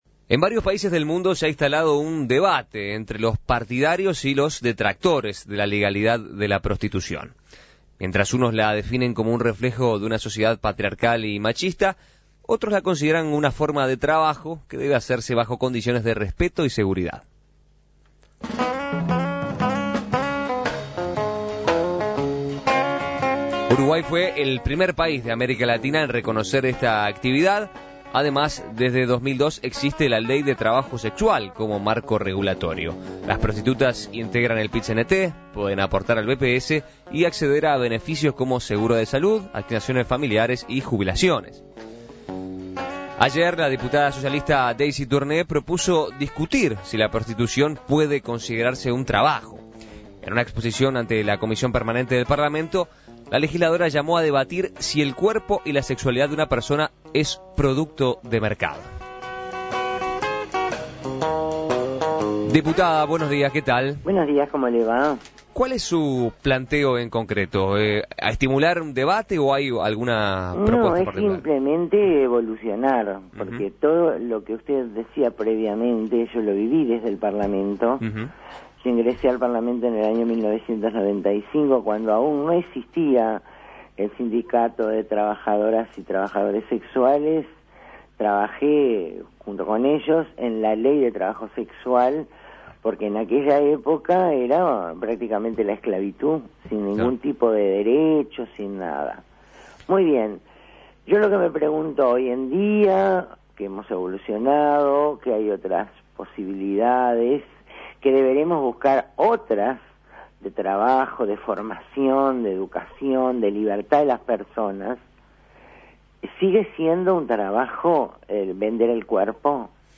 Uruguay no ha quedado exento de ese debate, la diputada Daisy Tourné planteó este tema en la Comisión Permanente de Parlamento y dialogó con En Perspectiva al respecto.